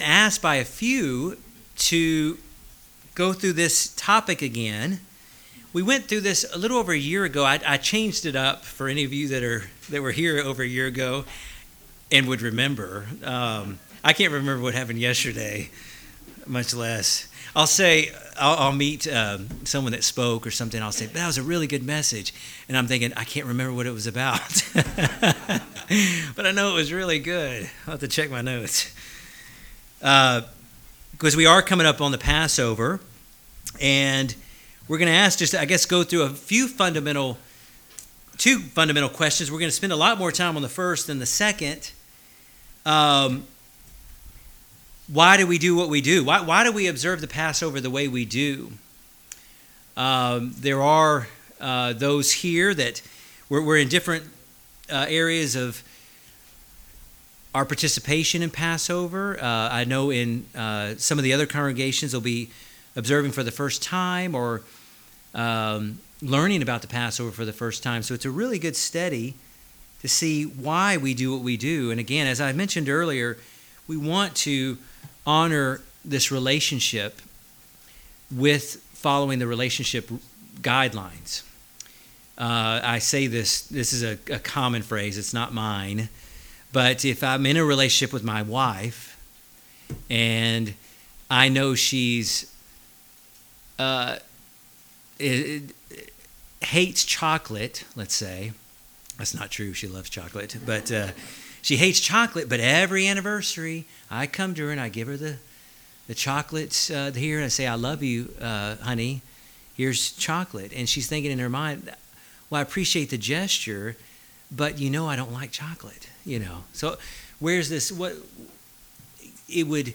Bible Study: Why Do We Keep the Passover the Way We Do?